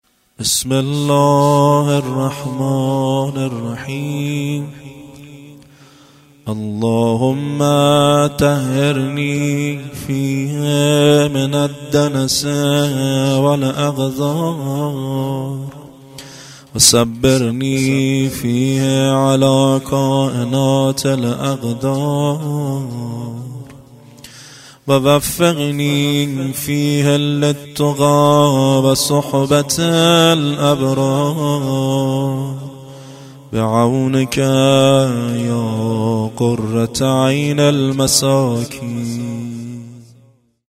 دعای ایام ماه مبارک رمضان